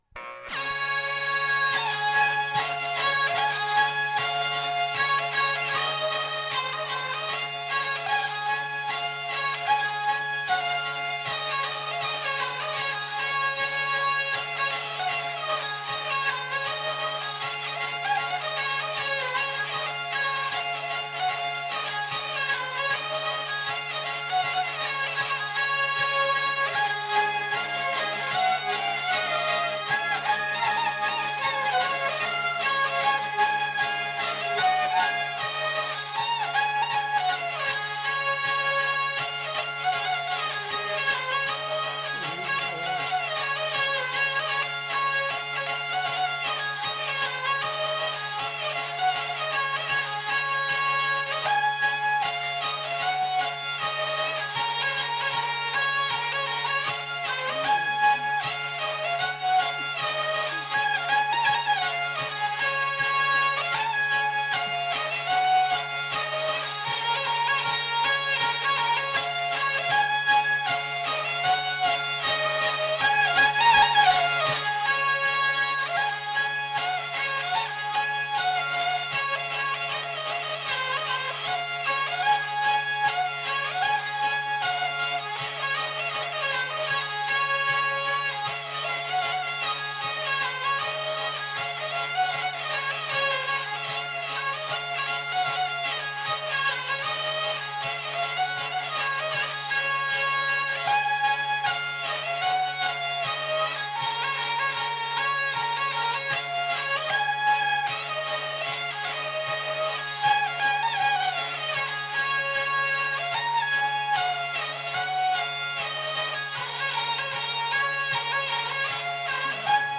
Lieu : Vielle-Soubiran
Genre : morceau instrumental
Instrument de musique : vielle à roue
Danse : scottish